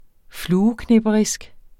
Udtale [ -ˌknεbʌʁisg ]